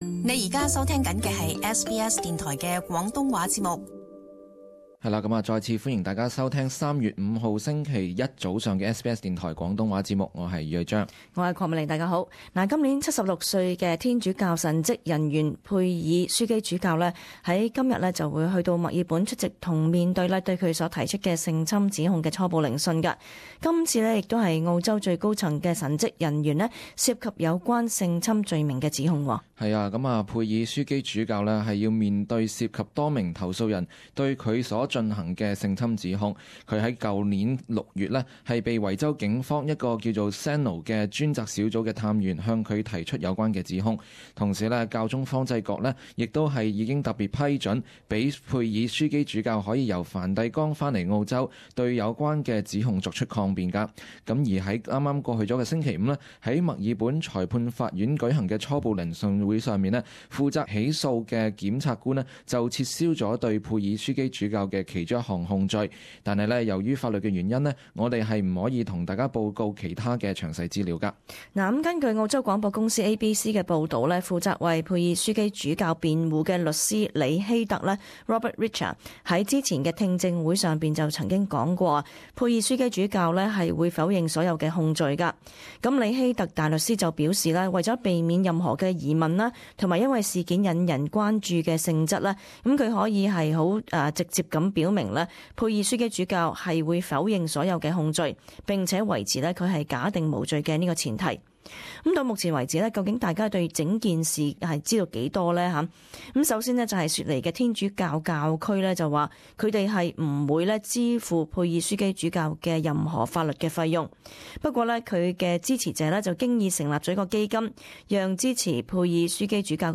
【時事報導】佩爾出席性侵指控初級偵訊